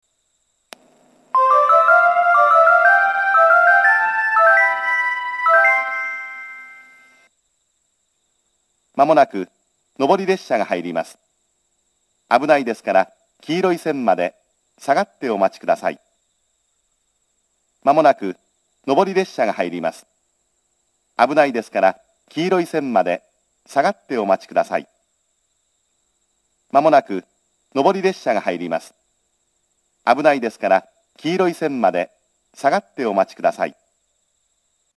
接近放送は秋田支社の新幹線並行区間標準のものが使用されています。
１番線上り接近放送
jinguji-1bannsenn-nobori-sekkinn.mp3